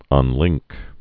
(ŭn-lĭngk)